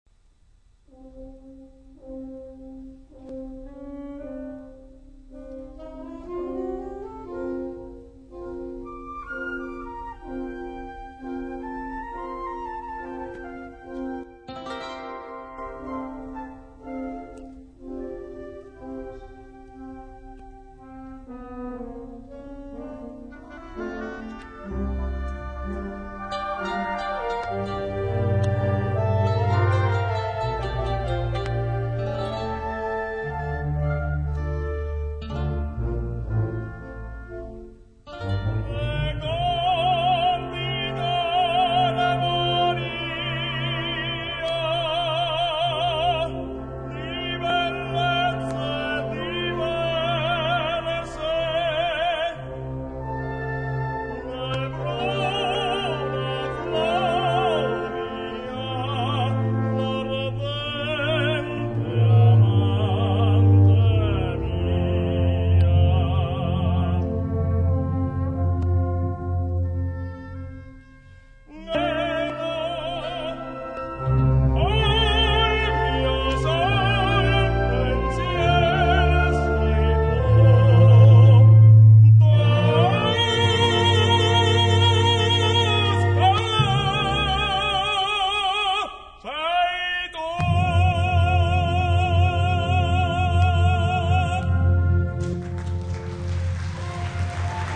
Per Tenore e Banda                       o strumento solista